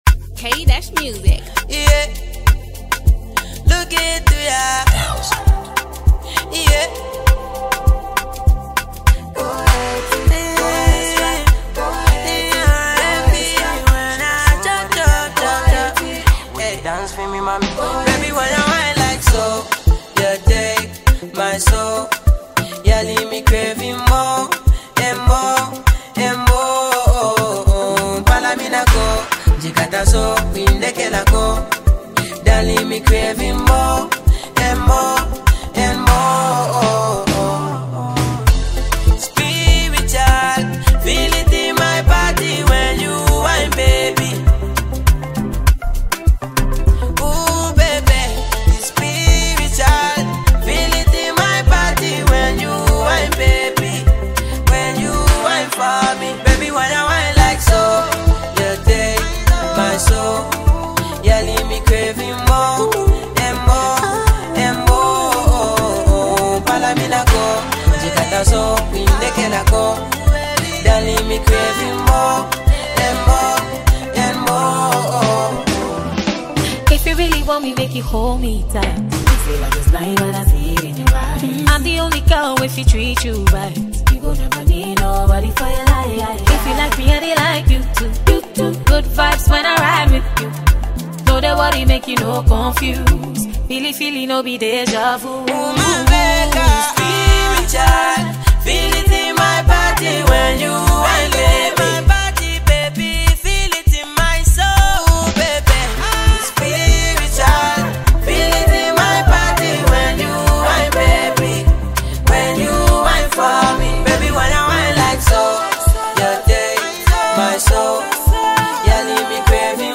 Ghanaian songstress
smooth blend of Afrobeat and R&B vibes